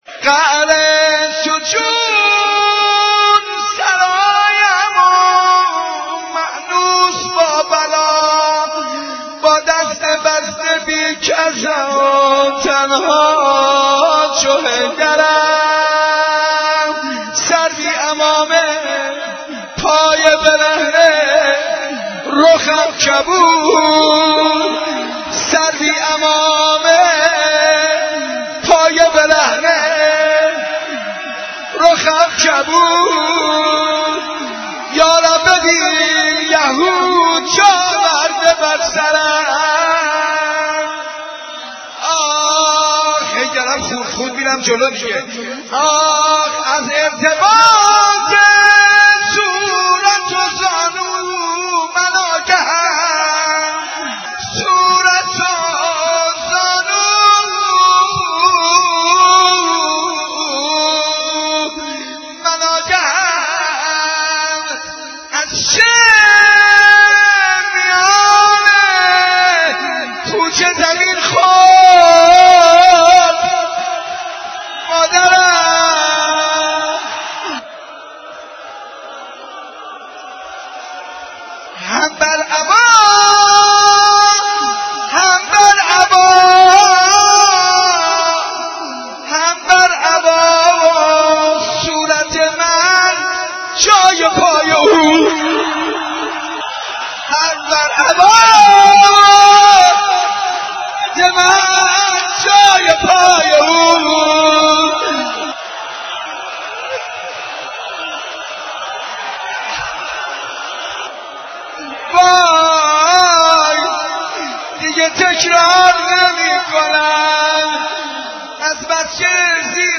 مرثیه خوانی شهادت امام موسی کاظم علیه السلام
روضه و مرثیه ها